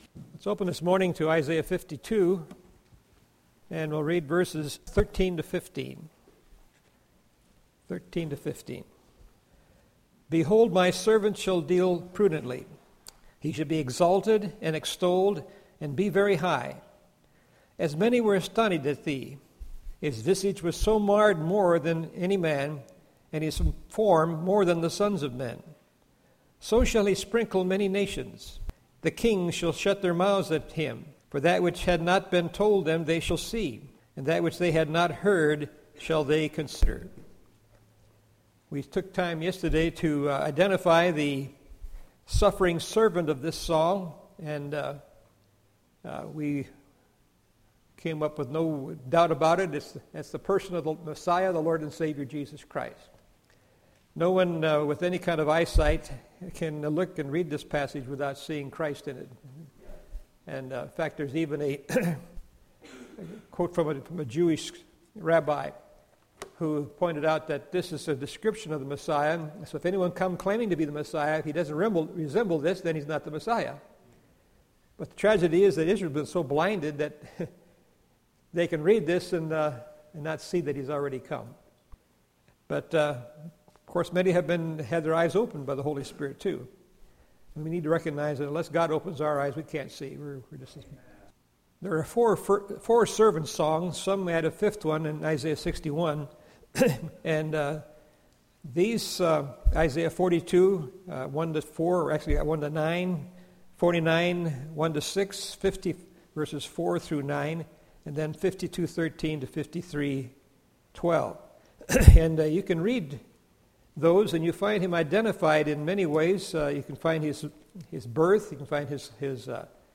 Series: 2012 July Conference